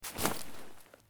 rgd6_throw.ogg